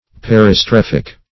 Search Result for " peristrephic" : The Collaborative International Dictionary of English v.0.48: Peristrephic \Per`i*streph"ic\, a. [Gr.